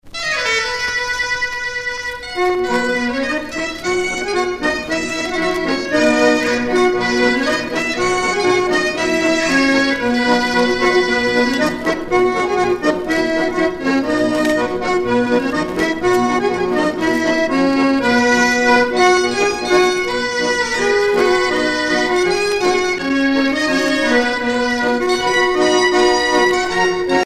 danse : polka des bébés ou badoise
Pièce musicale éditée